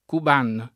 Cuban [ kub # n ]